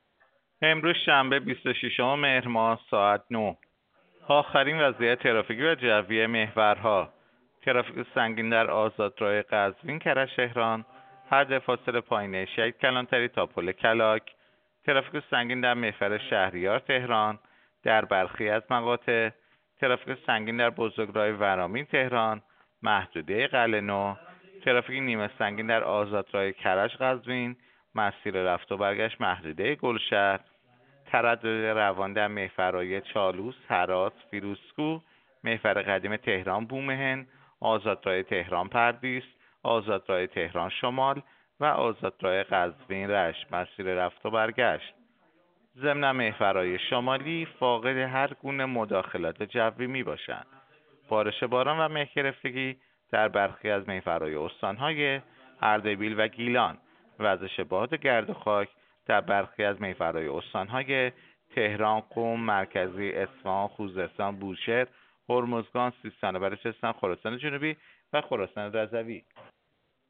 گزارش رادیو اینترنتی از آخرین وضعیت ترافیکی جاده‌ها ساعت ۹ بیست‌وششم مهر؛